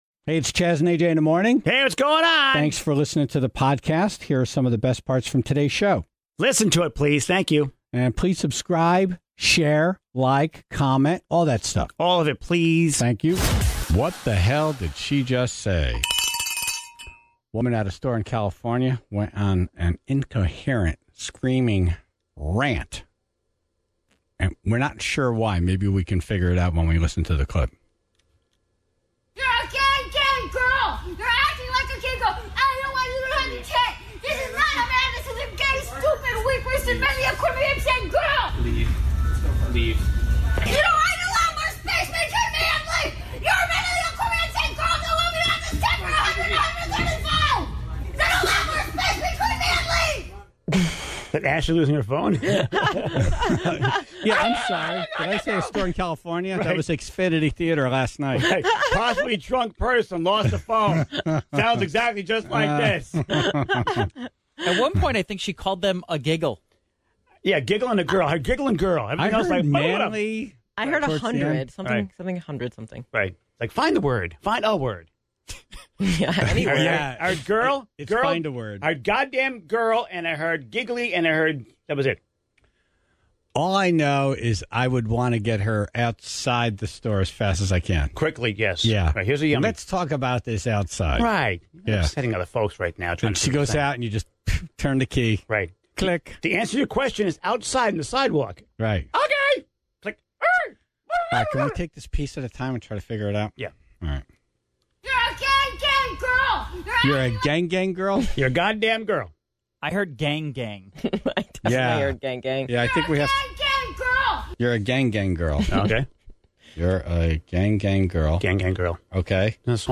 Dumb Ass News - incoherent woman kicked out of a store for shouting things no one can understand (0:00)